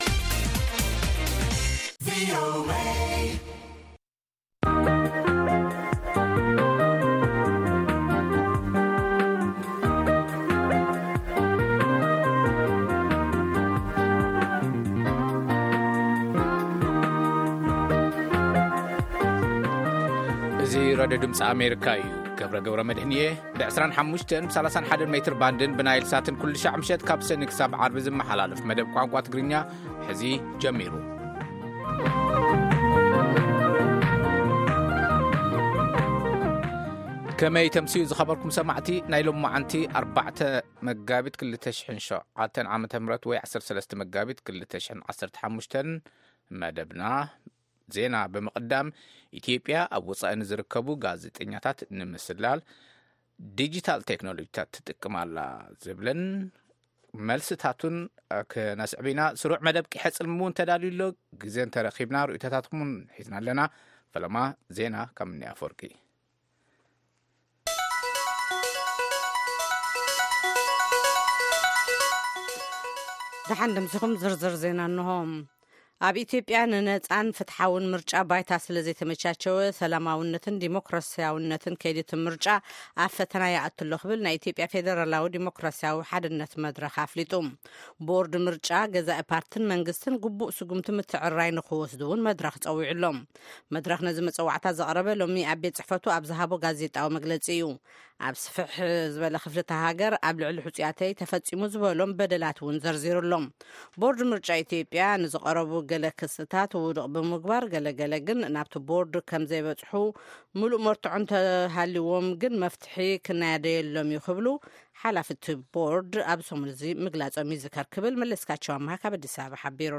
ፈነወ ትግርኛ ብናይ`ዚ መዓልቲ ዓበይቲ ዜና ይጅምር ። ካብ ኤርትራን ኢትዮጵያን ዝረኽቦም ቃለ-መጠይቓትን ሰሙናዊ መደባትን ድማ የስዕብ ። ሰሙናዊ መደባት ዓርቢ፡ ቂሔ-ጽልሚ / ፍሉይ መደብ/ ሕቶን መልስን